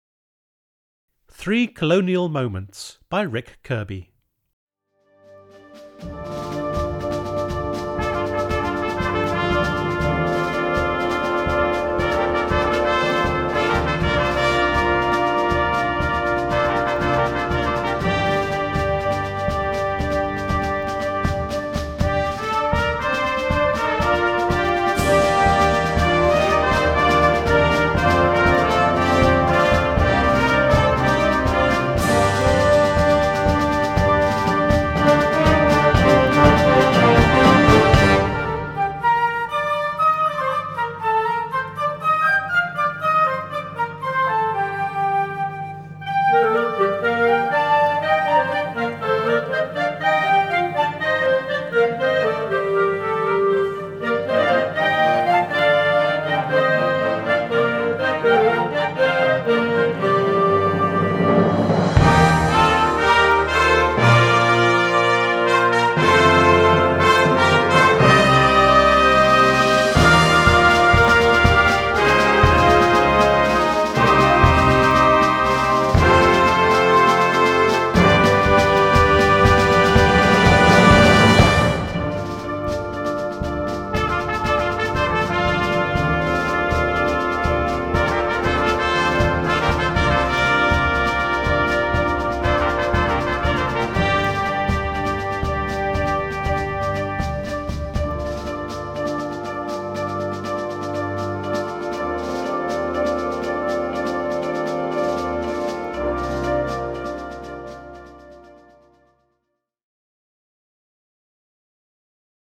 suite for band
the lively dance